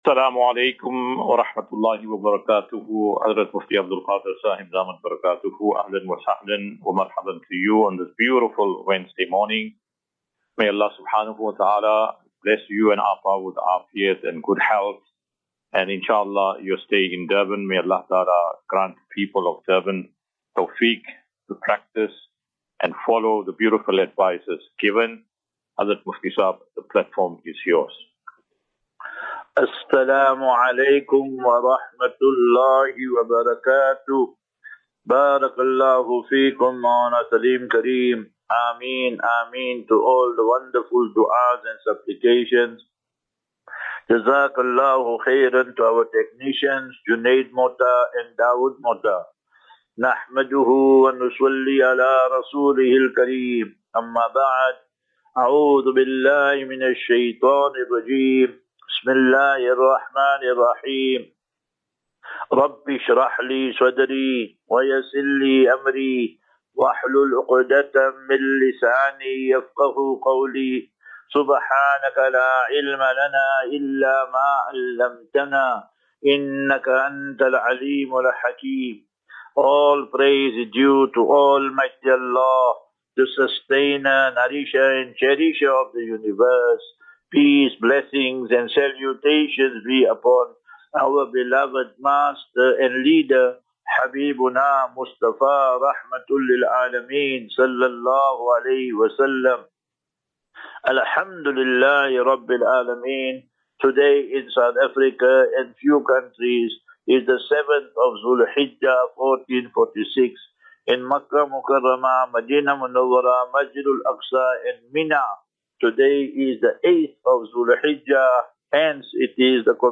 Assafinatu - Illal - Jannah. QnA.